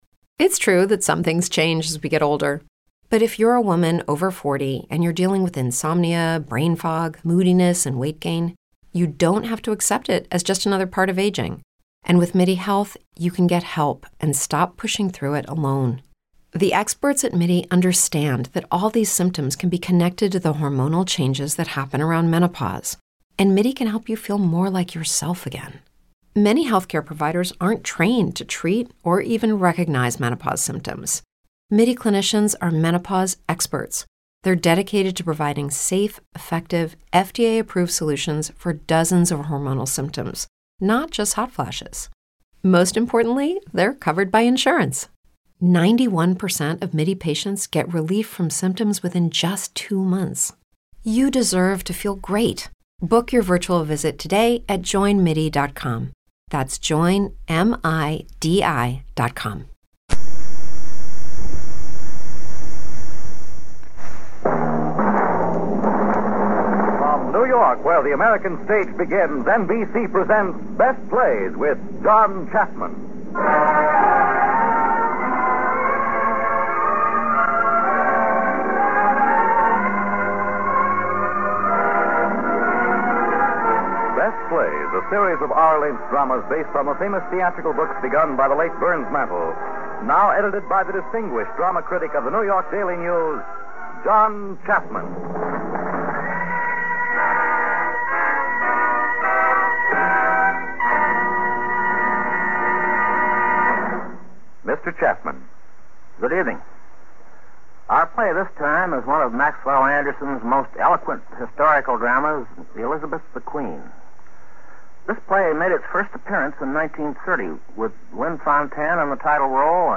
Going on-air from 1952 to 1953, the Best Plays was an NBC Radio program that featured some of the most excellent theatric plays ever created. Some of the best ones featured were dramatic or comedic plays.